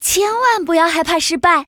文件 文件历史 文件用途 全域文件用途 Choboong_fw_04.ogg （Ogg Vorbis声音文件，长度0.0秒，0 bps，文件大小：23 KB） 源地址:游戏语音 文件历史 点击某个日期/时间查看对应时刻的文件。